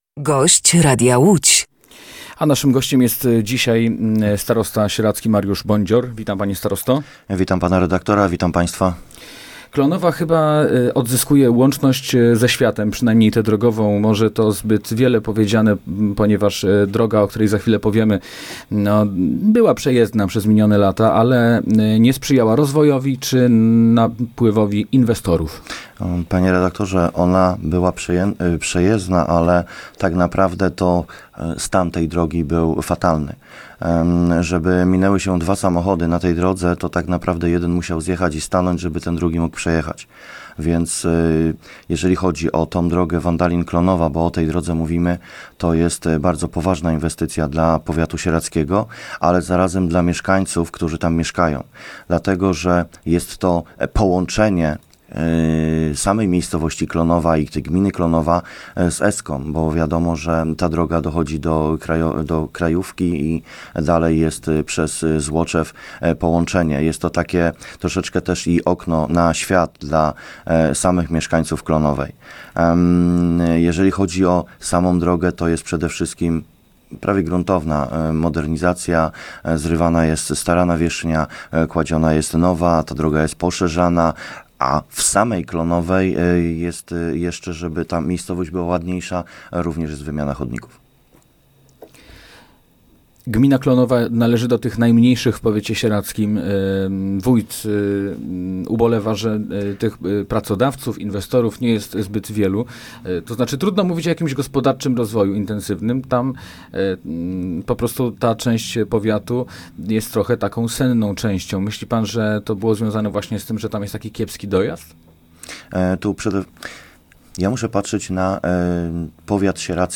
Gościem Radia Łódź Nad Wartą był starosta sieradzki, Mariusz Bądzior.
Posłuchaj całej rozmowy: Nazwa Plik Autor – brak tytułu – audio (m4a) audio (oga) Warto przeczytać Nasze dzieci.